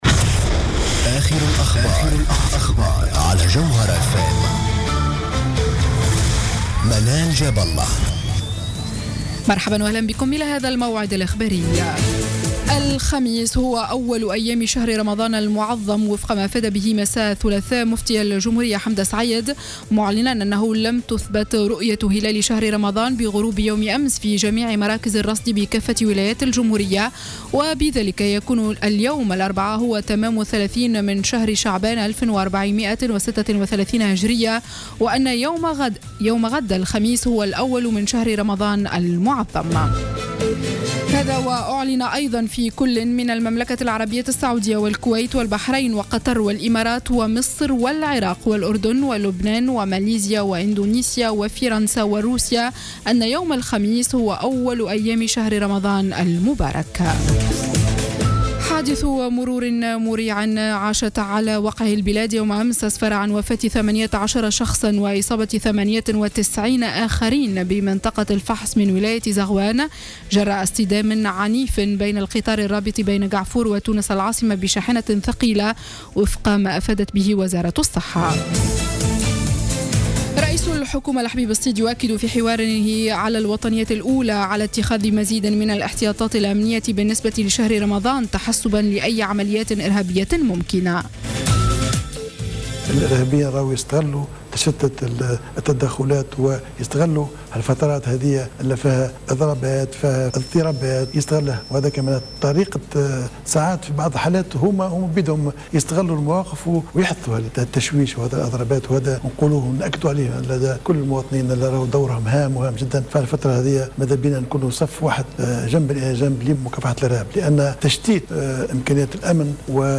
نشرة أخبار منتصف الليل ليوم الإربعاء 17 جوان 2015